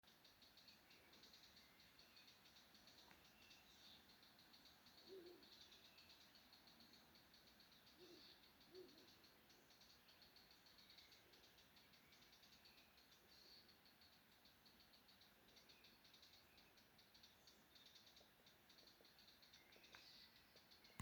Melngalvas ķauķis, Sylvia atricapilla
Administratīvā teritorijaKrustpils novads
StatussUztraukuma uzvedība vai saucieni (U)